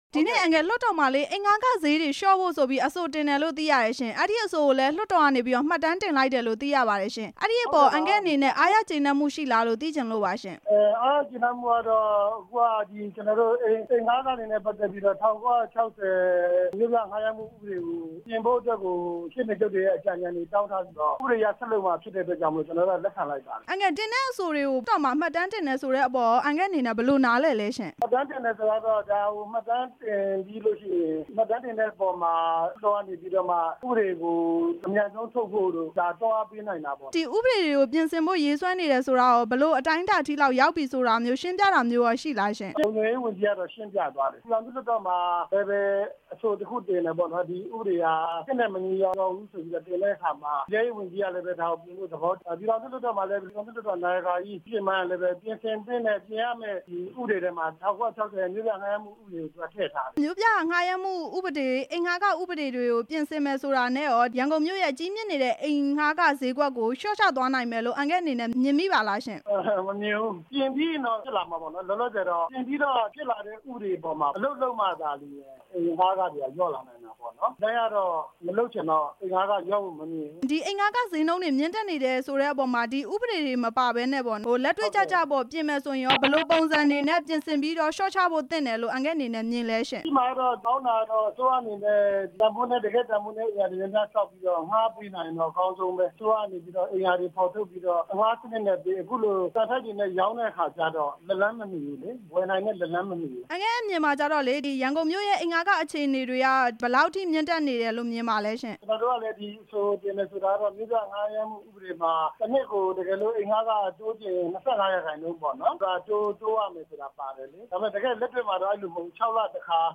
လွှတ်တော်ကိုယ်စားလှယ် ဦးသောင်းကျော်နဲ့ မေးမြန်းချက်